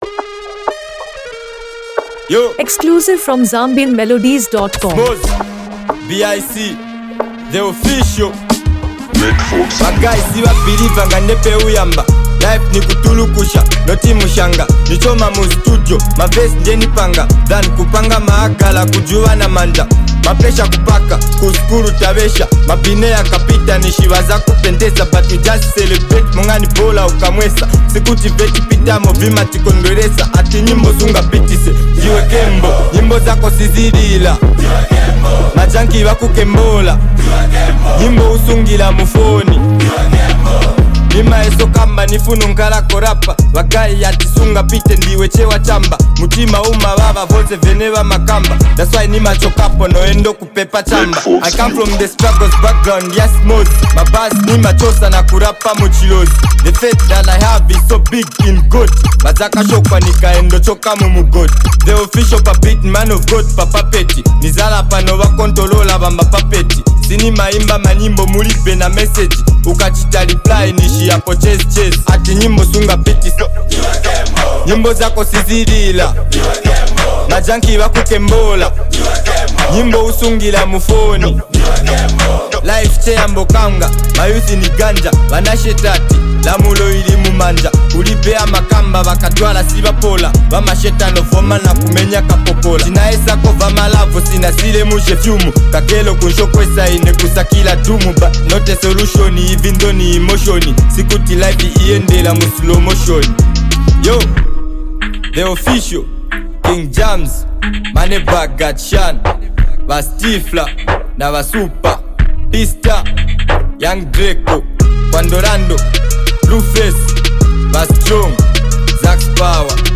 giving it a professional and radio-ready finish.